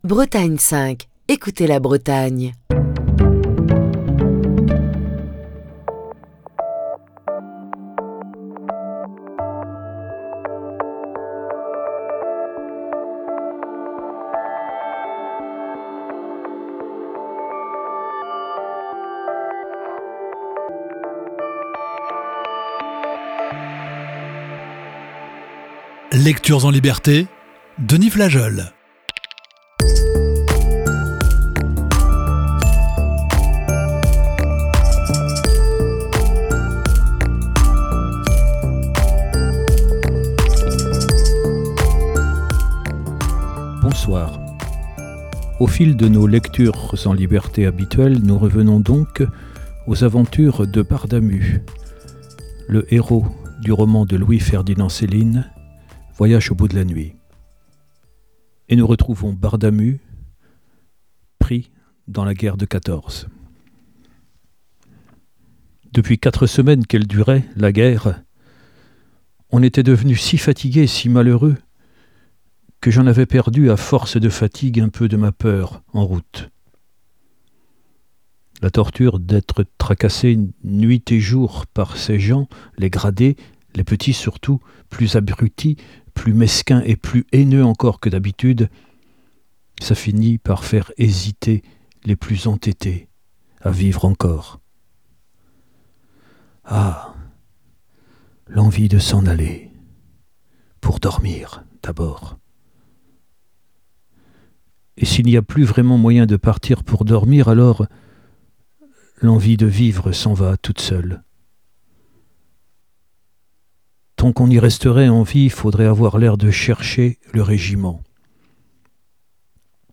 Lecture(s) en liberté